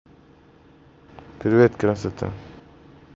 Звук приветствия с улыбкой
• Категория: Привет(приветствие)
• Качество: Высокое